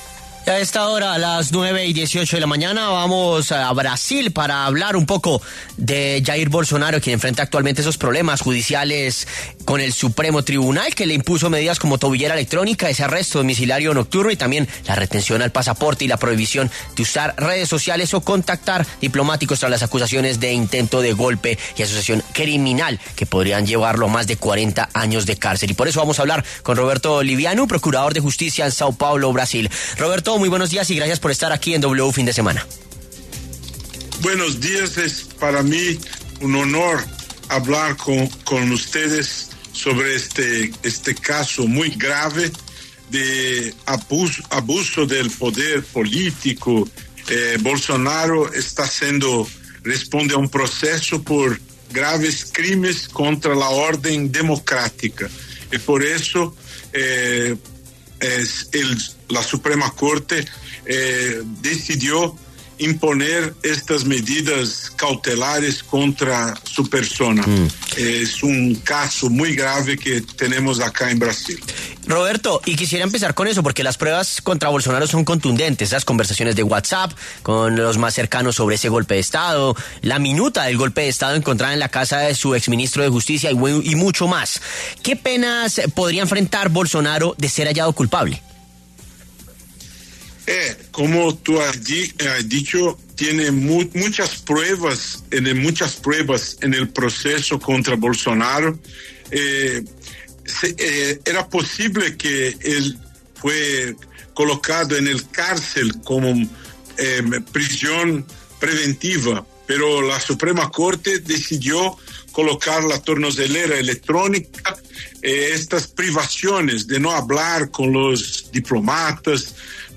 Roberto Livinu, procurador de Justicia del Ministerio Público de Sao Paulo, dialogó con W Fin De Semana acerca de la posible condena contra el expresidente de Brasil, Jair Bolsonaro, por un presunto intento de golpe de Estado en el 2022 contra Lula da Silva.